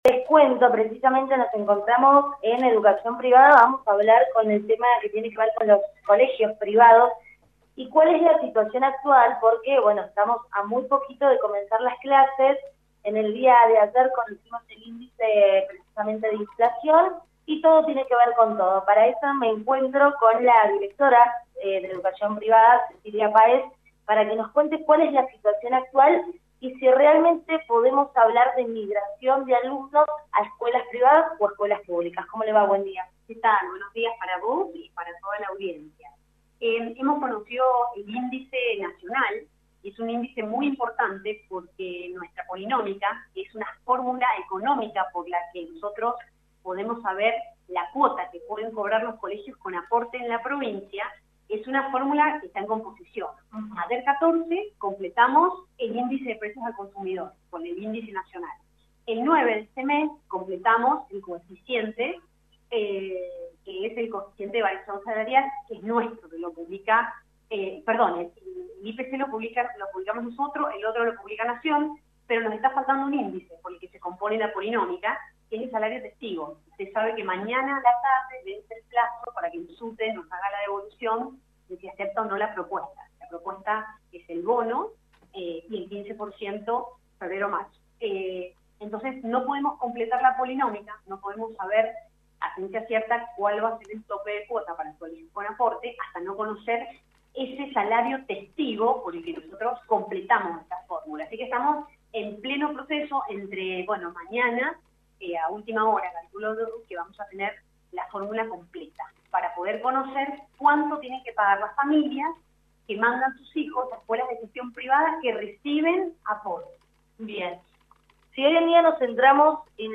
Móvil de LVDiez- Cecilia Paez, Directora de Educación Privada “Sin la fórmula completa hay un 170% de auemnto anual”